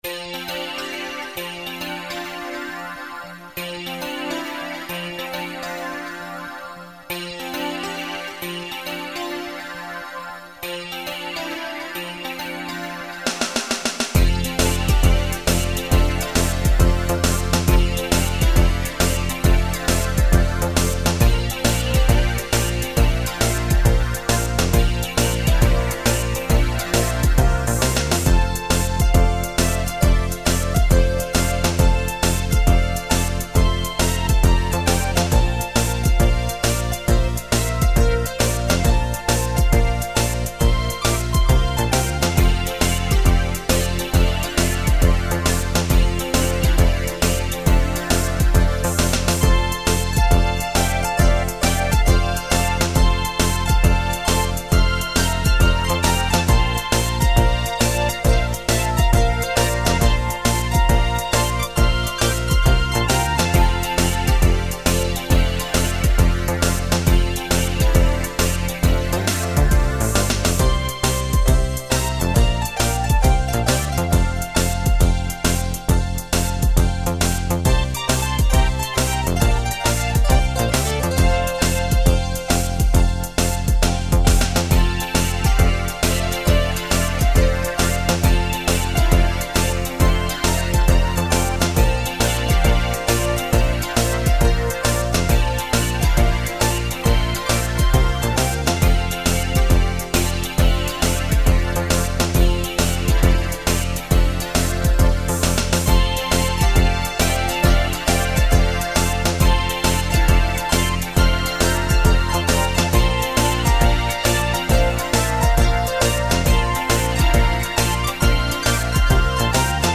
The songs are recorded with a MIDI sequencer.
4:30 - 136 bpm - 12 September 1999